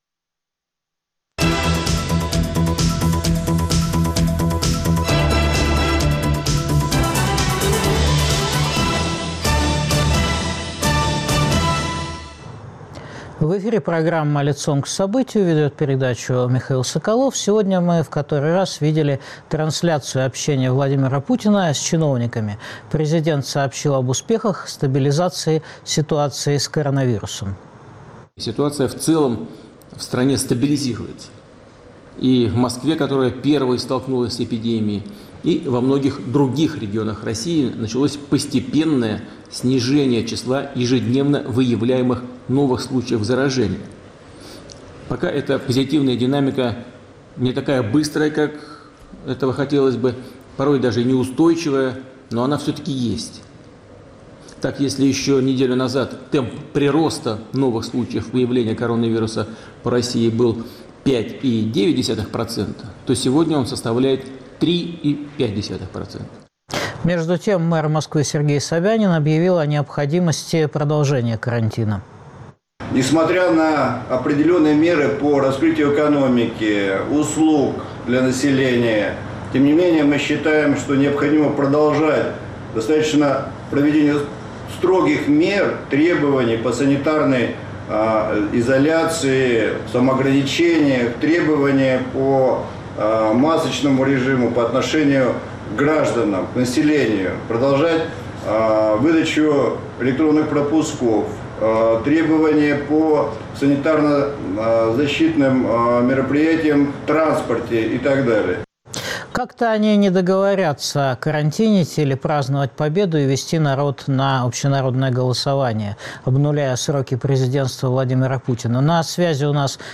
Провалила ли власть борьбу с эпидемией? Зачем народ выведут на голосование по Конституции? Обсуждают политик Владимир Рыжков, политолог Сергей Марков.